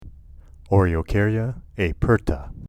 Pronunciation/Pronunciación:
O-re-o-cár-ya  a-pér-ta